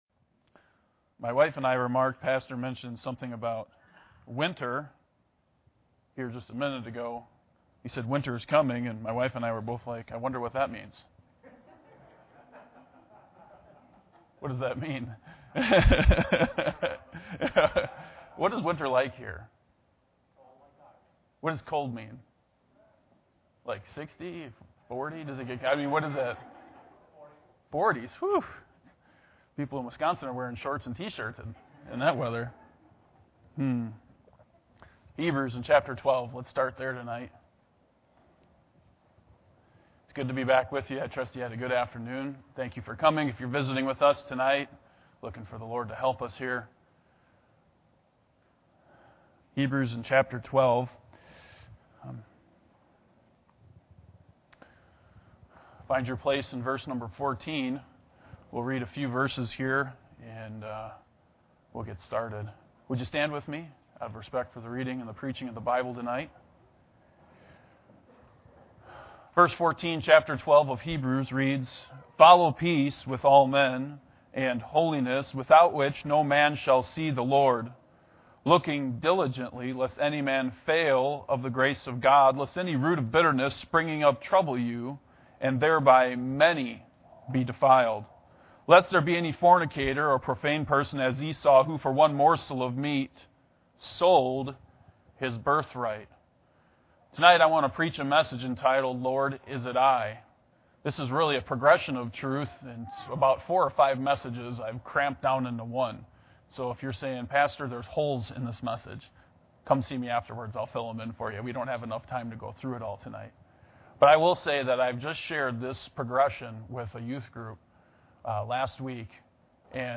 Hebrews 12:14-16 Service Type: Sunday Evening Bible Text